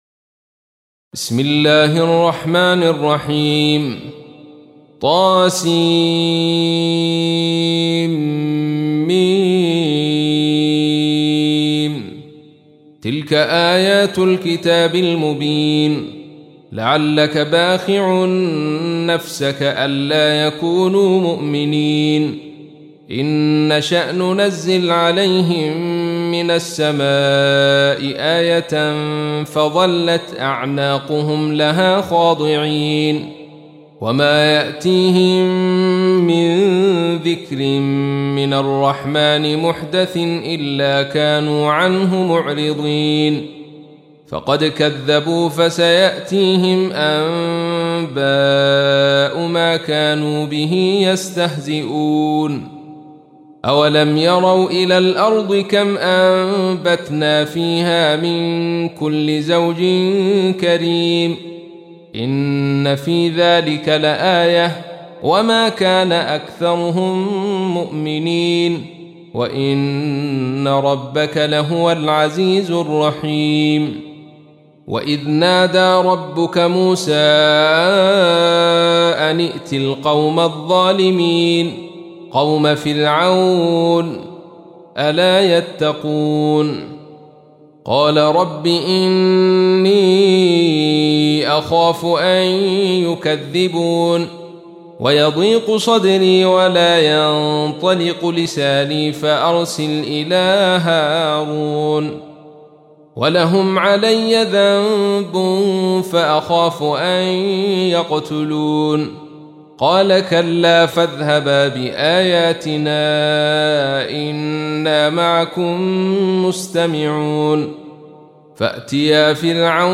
تحميل : 26. سورة الشعراء / القارئ عبد الرشيد صوفي / القرآن الكريم / موقع يا حسين